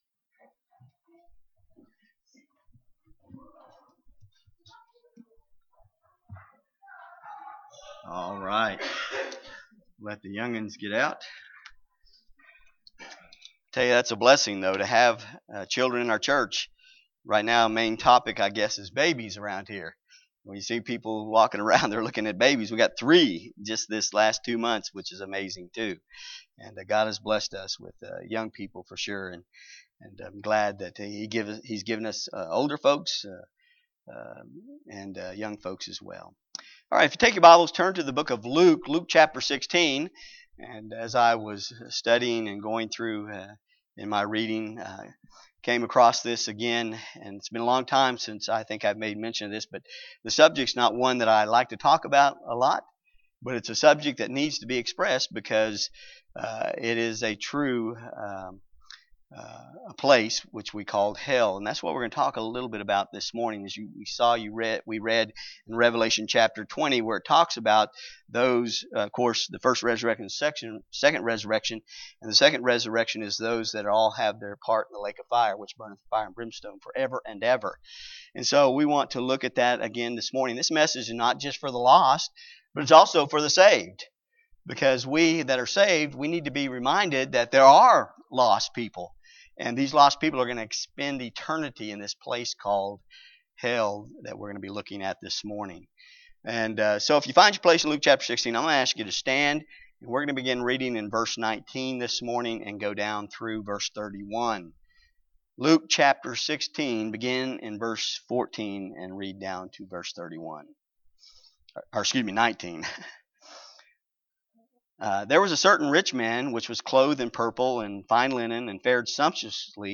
Series: CBC Sermons Passage: Luke 16:14-31 Service Type: Sunday AM